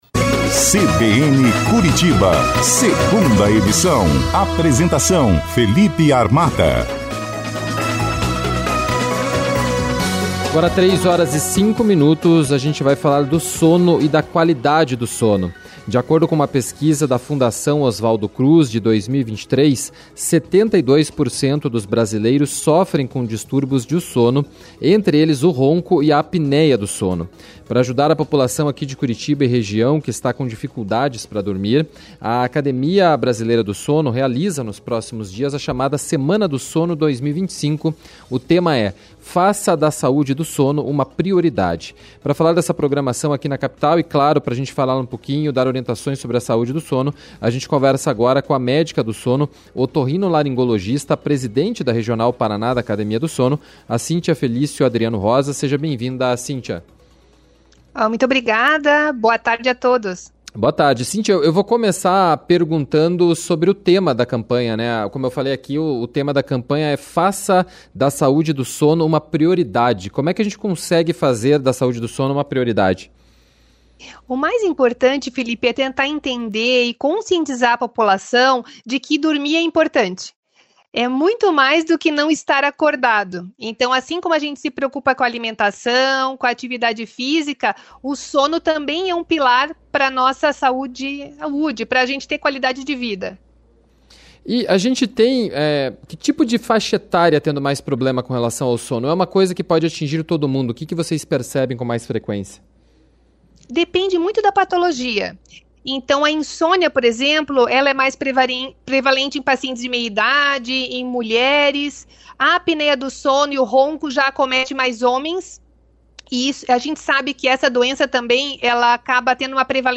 ENTREVISTA-13.03.-TARDE.mp3